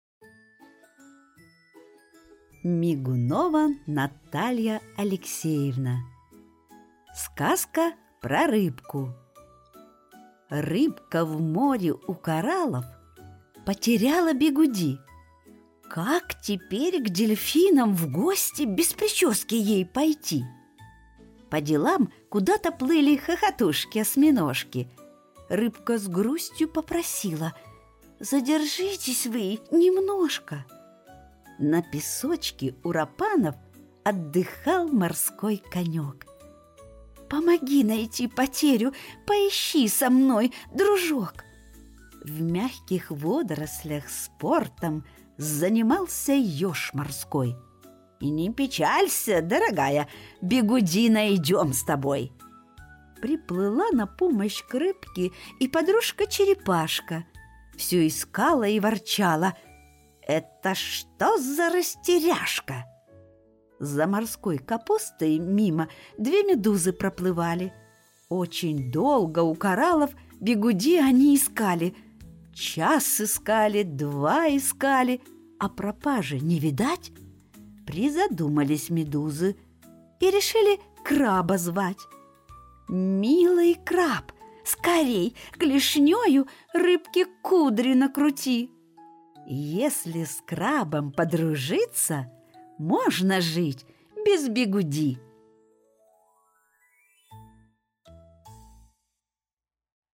Сказка про рыбку (аудиоверсия) – Мигунова Н.